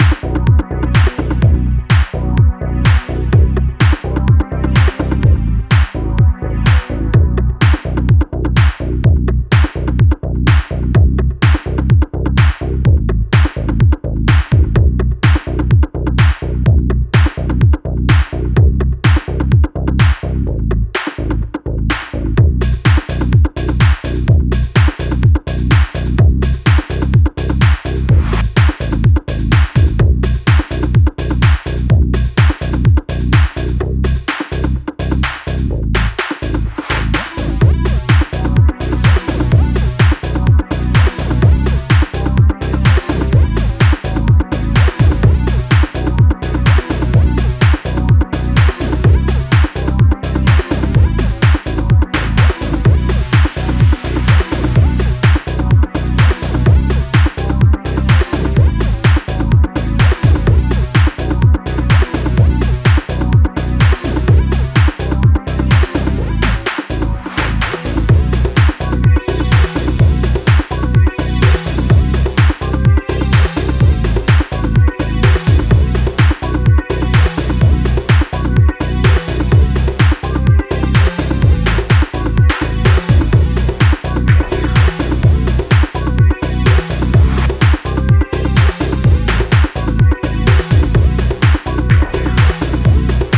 deep and progressive journey
melodic techno style